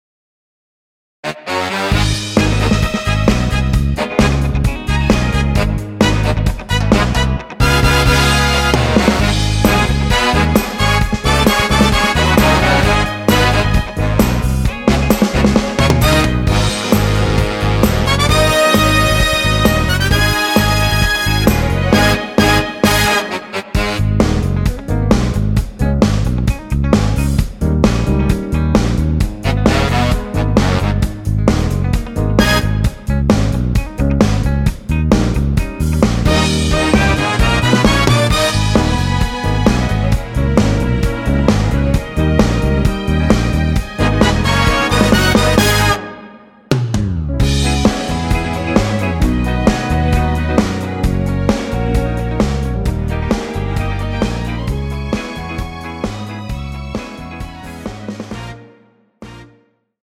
원키에서(-1)내린 MR입니다.
Abm
앞부분30초, 뒷부분30초씩 편집해서 올려 드리고 있습니다.
중간에 음이 끈어지고 다시 나오는 이유는